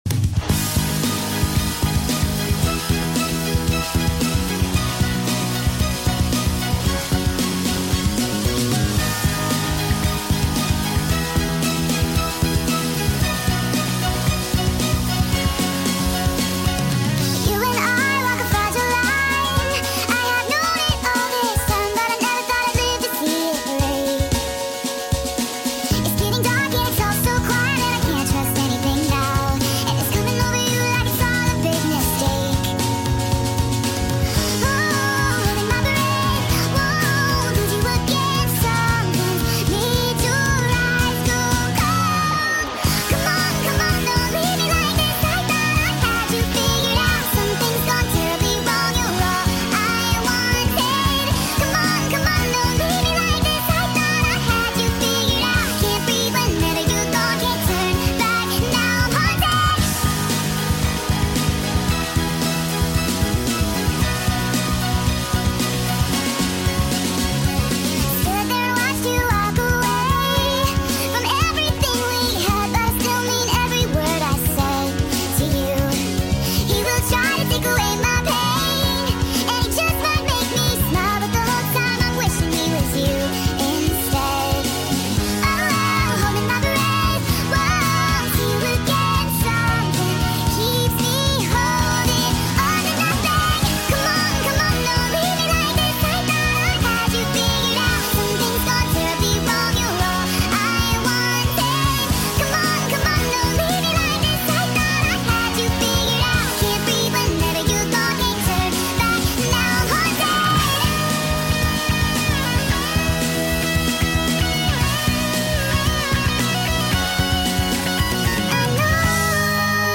FULL SONG SPED UP!!